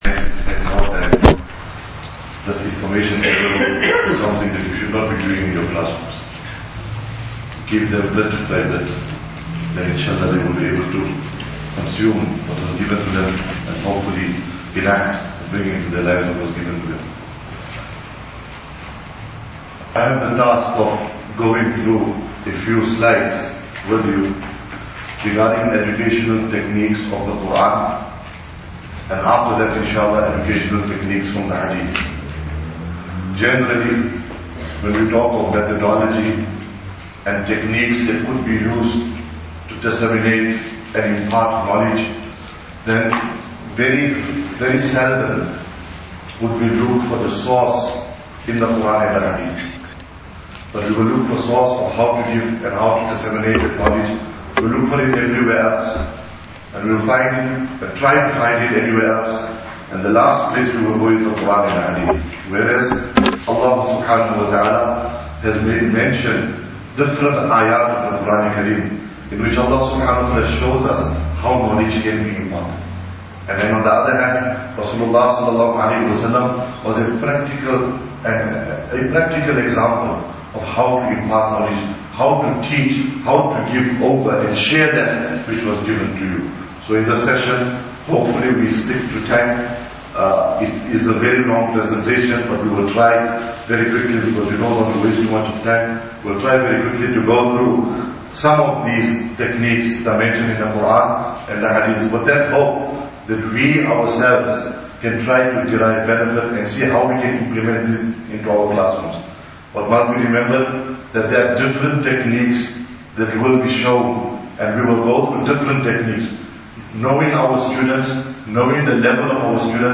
Lecture 1 – Classroom Management & Divine Methodology (Sunday 30th April 2017)
Islamic Studies Teachers Professional Development Workshop at Masjid Ibrahim.